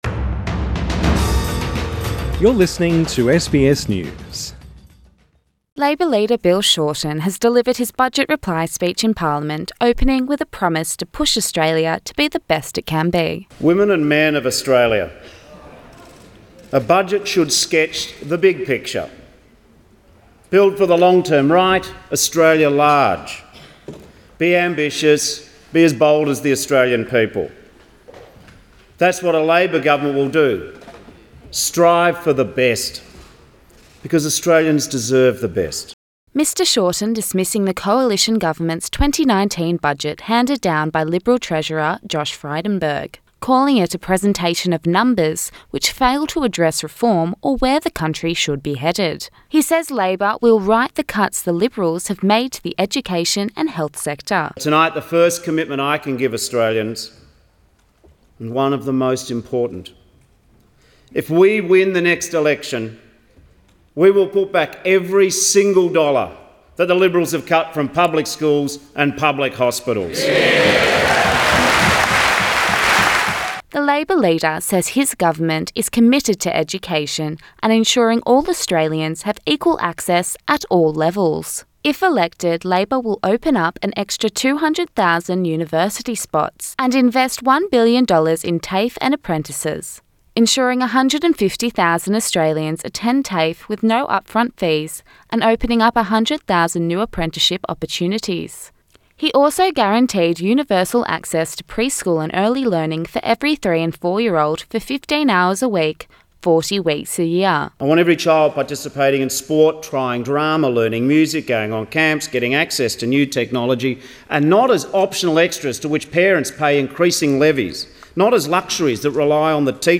Health is a focus as Labor Leader Bill Shorten has delivers his budget reply speech in parliament.